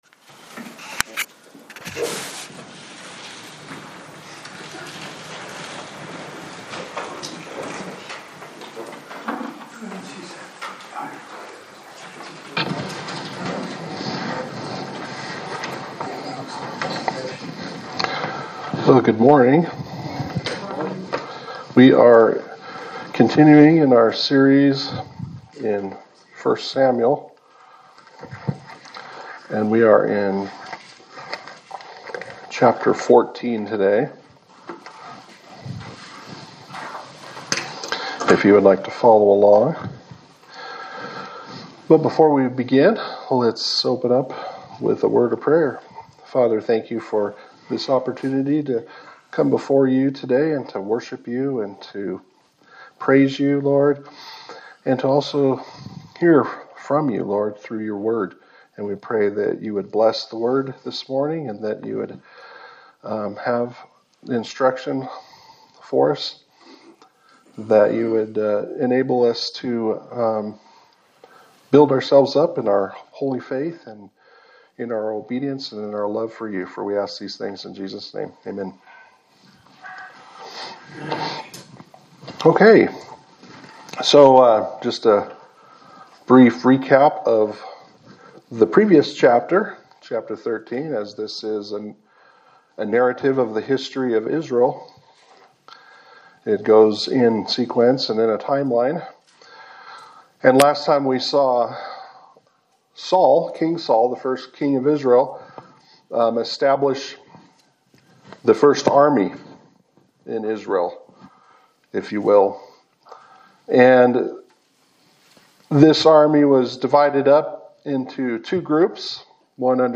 Sermon for November 9, 2025
Service Type: Sunday Service